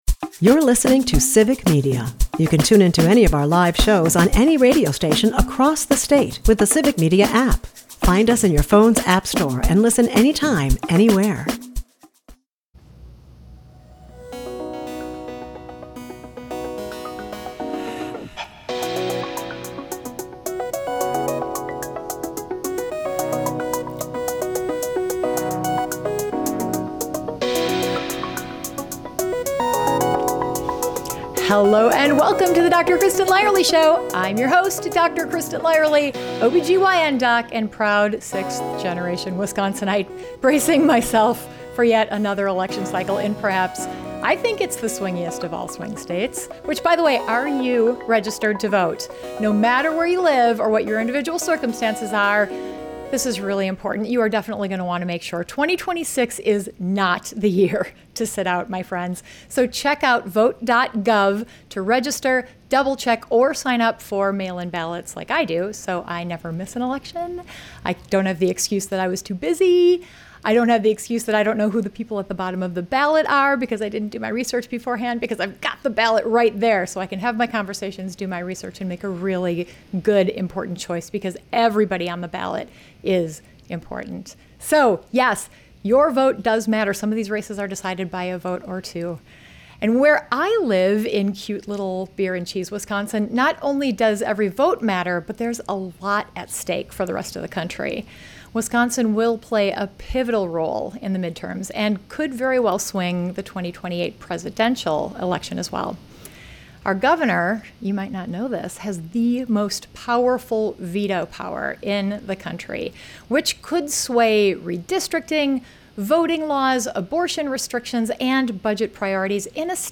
In this episode, I sit down with Barnes for a no-BS conversation about what happened in 2022, what he learned from losing, and why he thinks he can win this time.
Guests: Mandela Barnes Sat Jan 24, 2026 44:02 Listen Share My Interview with Wisconsin's Next Governor (Maybe) Mandela Barnes was Wisconsin's first Black Lieutenant Governor.